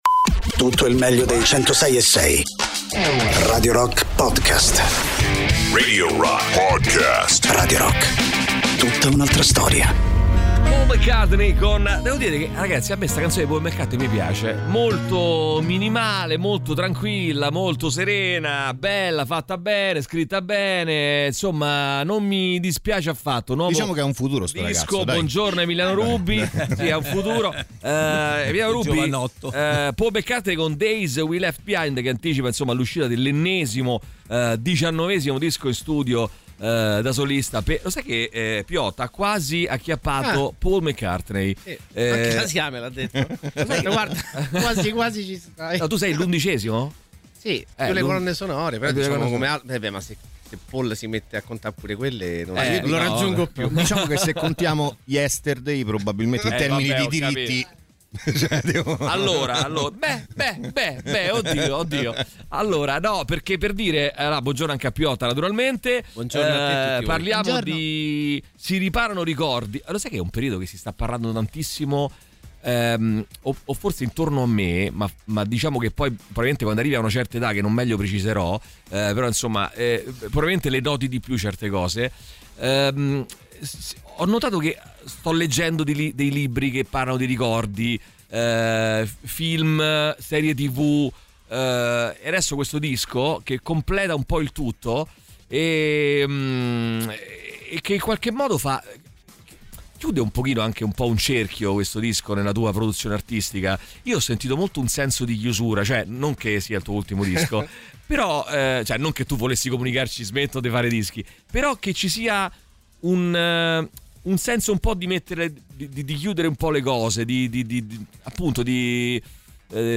Interviste: Piotta (02-04-26)